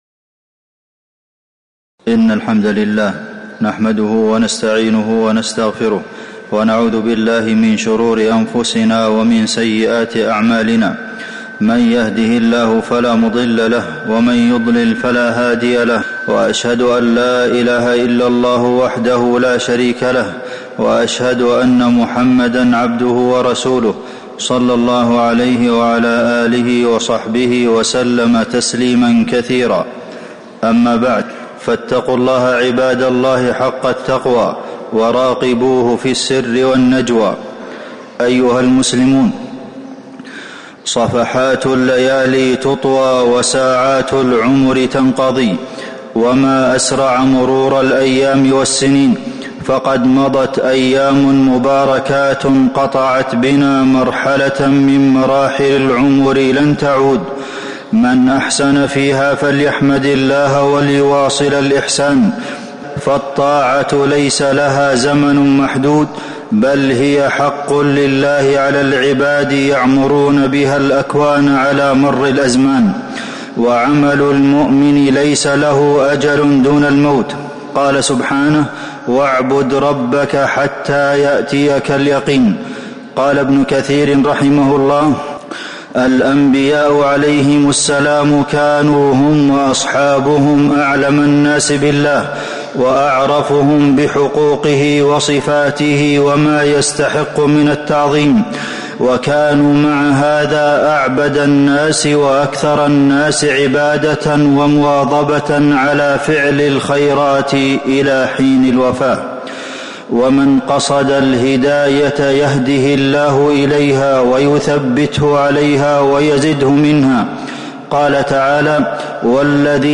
تاريخ النشر ٦ شوال ١٤٤٦ هـ المكان: المسجد النبوي الشيخ: فضيلة الشيخ د. عبدالمحسن بن محمد القاسم فضيلة الشيخ د. عبدالمحسن بن محمد القاسم ماذا بعد رمضان